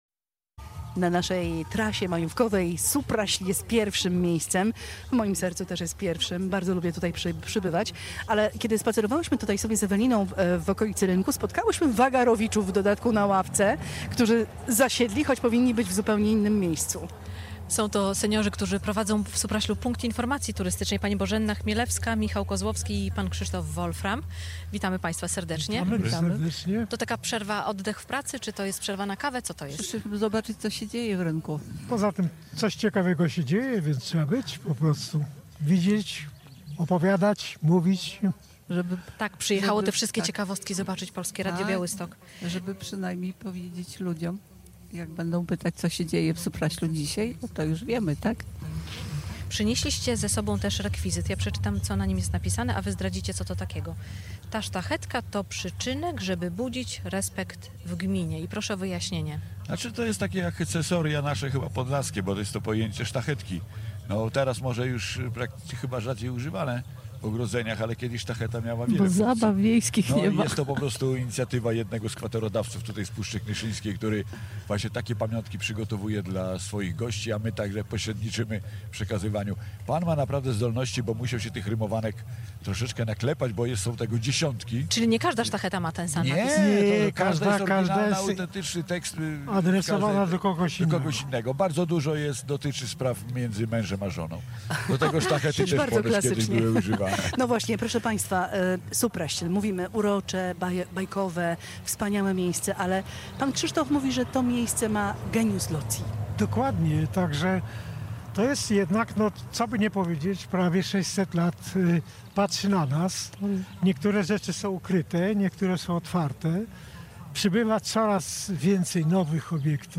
Z naszego plenerowego studia ustawionego przed Pałacem Buchholtzów zachęcaliśmy do spacerowania po miasteczku i relaksu w okolicznych lasach.